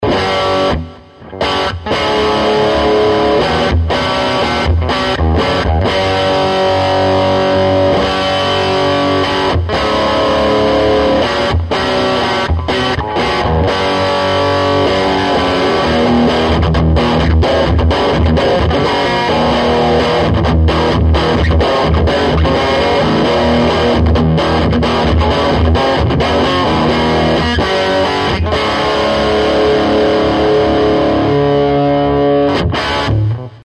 Used an SM57.
Lots of crunch and nice tone. The clips are full vol on the bright channel, MV at about 30%. Lead II with Carvin M22SD humbucker, single vol, no tone control.
Tube (256ms Delay)
tube_delay.mp3